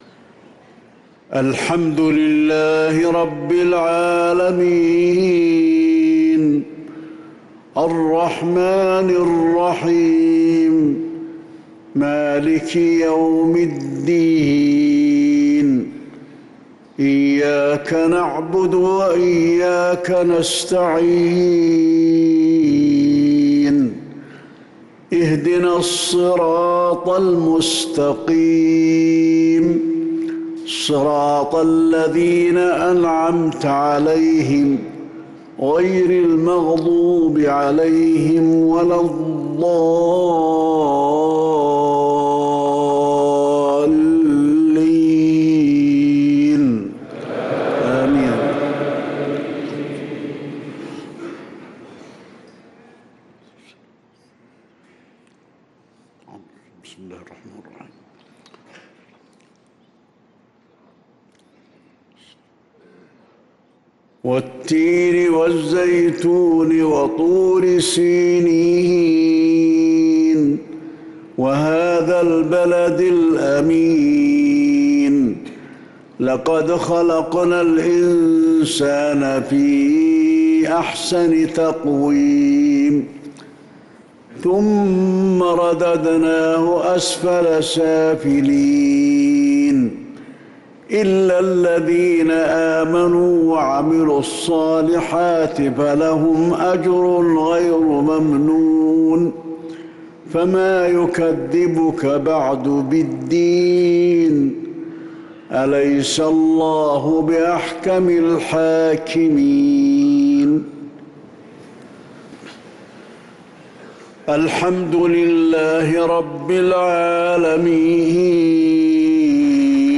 صلاة المغرب للقارئ علي الحذيفي 9 رمضان 1445 هـ